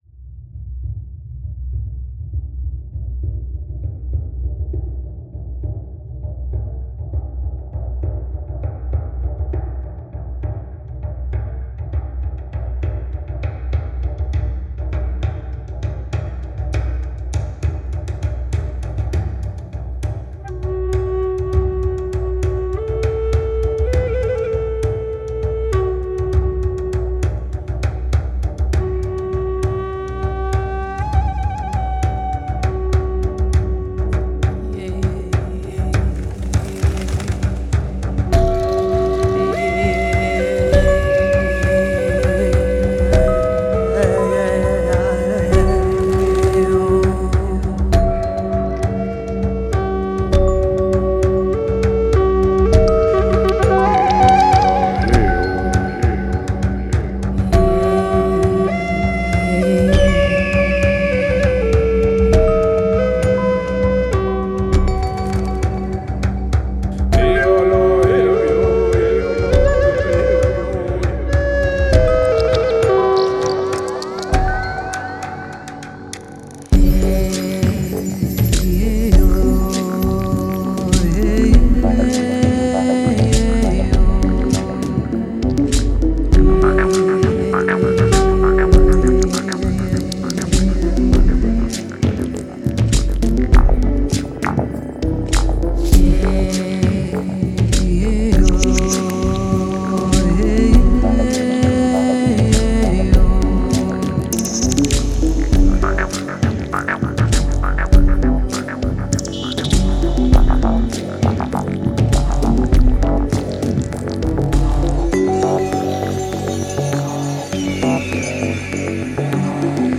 New Age Музыка шаманов Песни шаманов